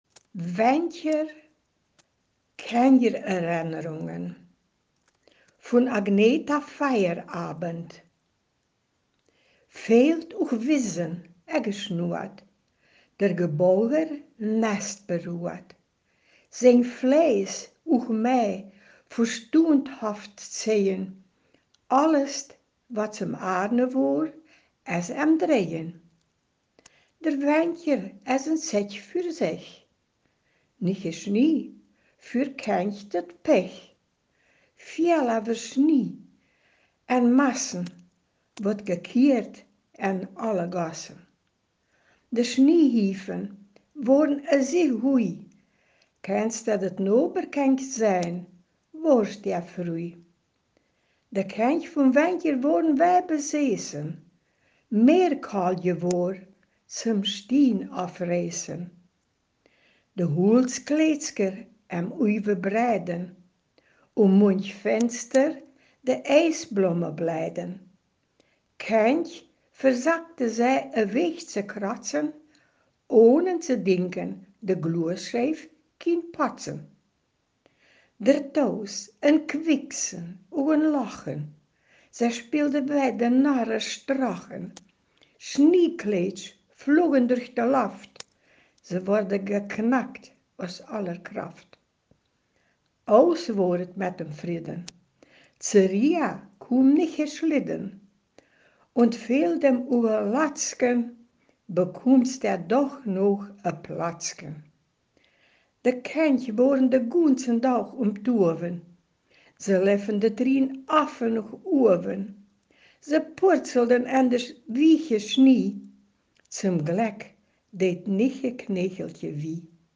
Ortsmundart: Hamlesch